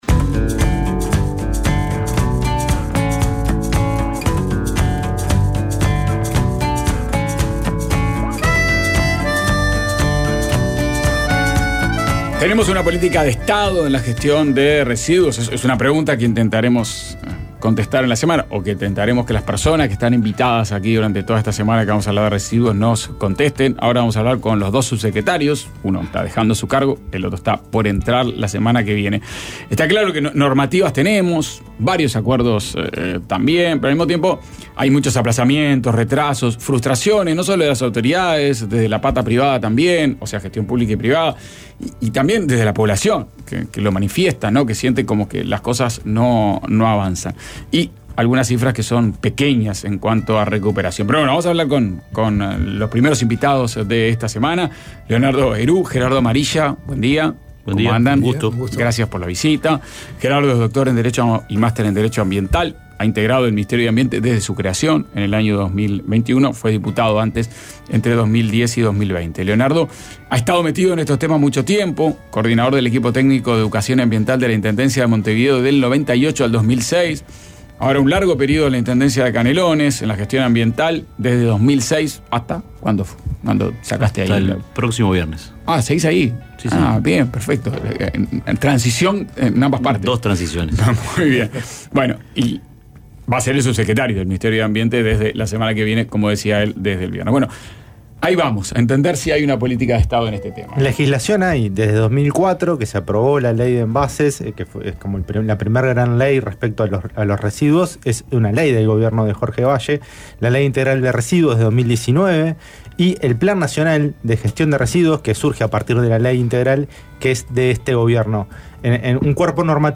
Ronda con el profesor de Farmacología